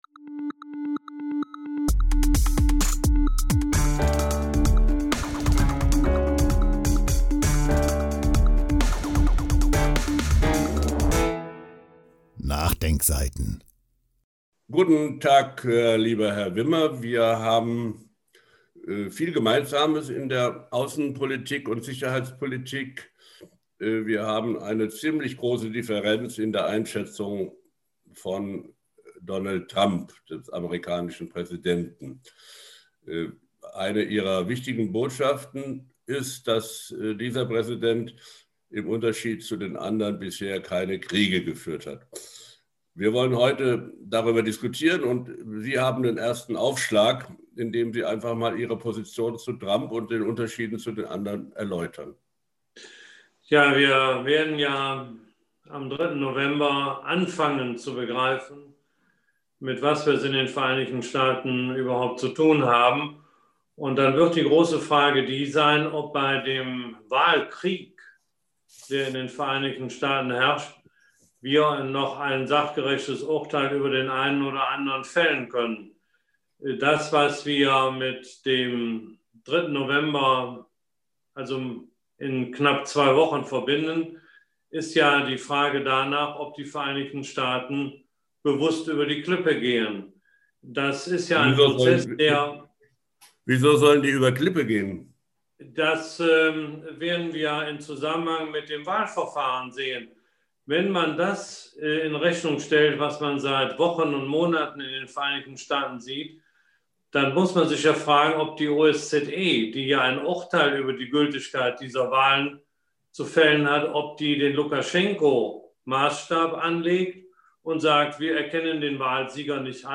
Video-Gespräch vom 22. Oktober 2020